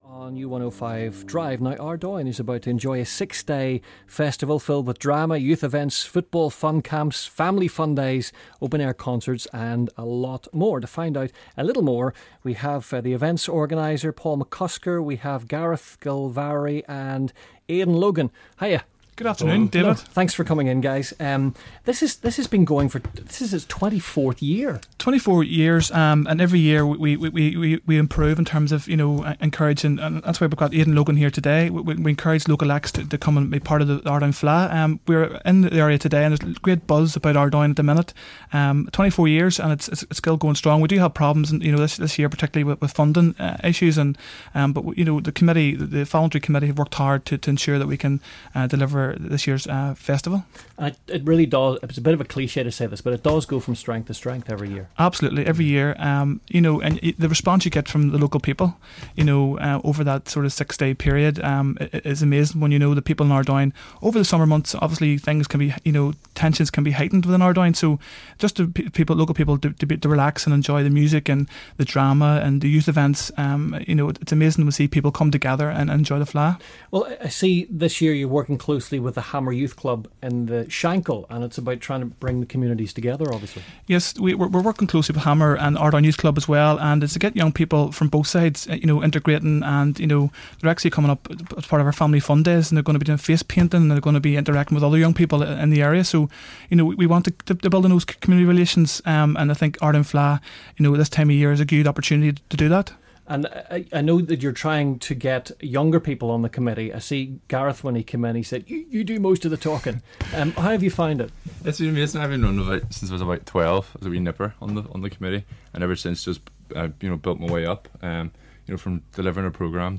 Ardoyne Fleadh Interview on Drive